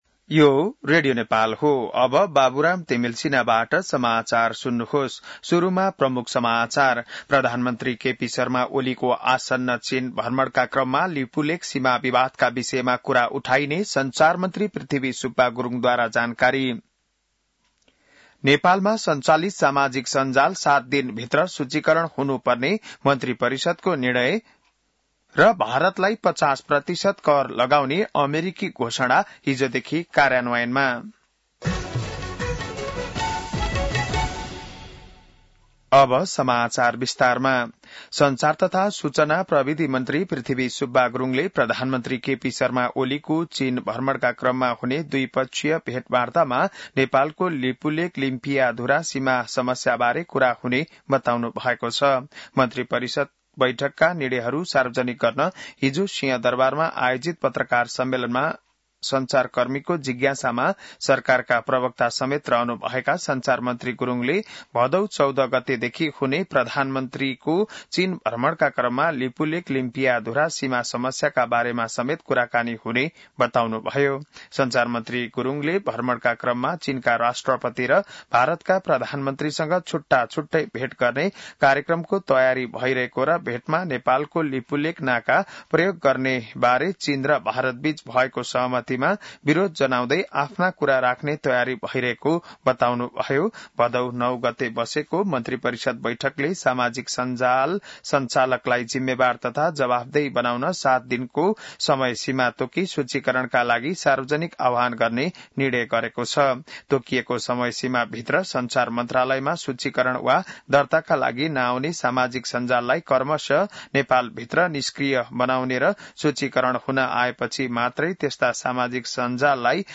बिहान ९ बजेको नेपाली समाचार : १२ भदौ , २०८२